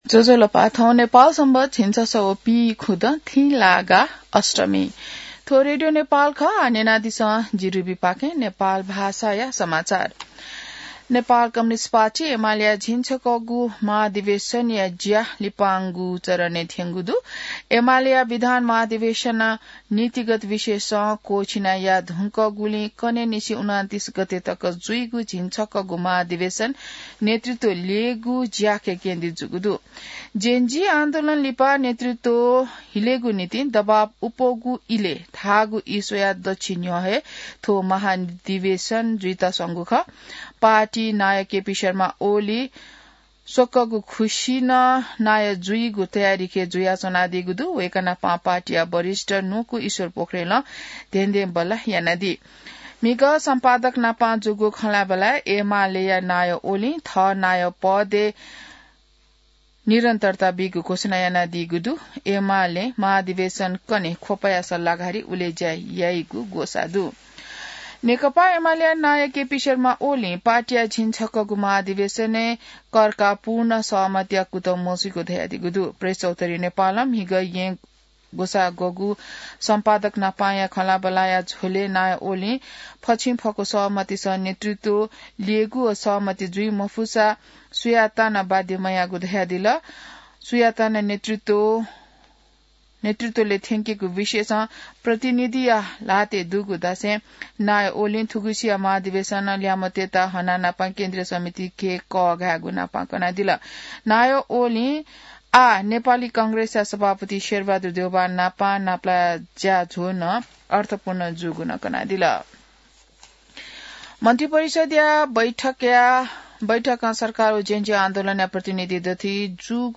नेपाल भाषामा समाचार : २६ मंसिर , २०८२